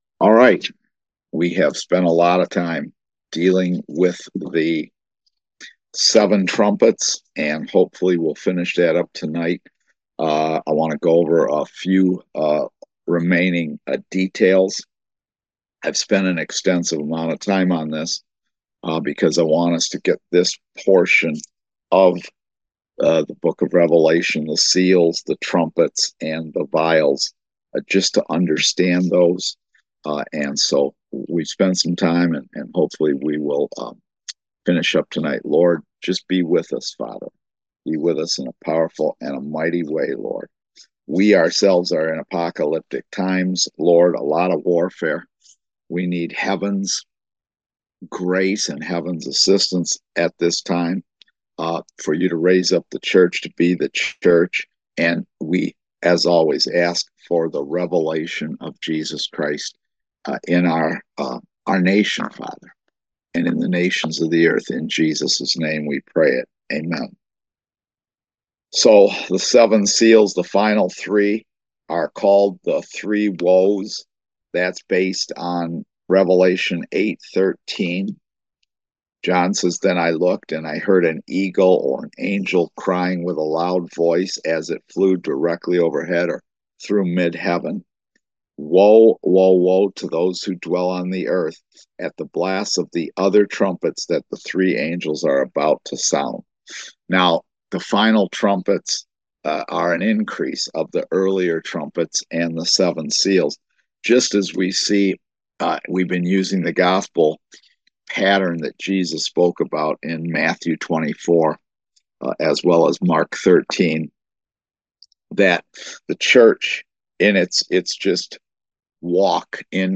Service Type: Kingdom Education Class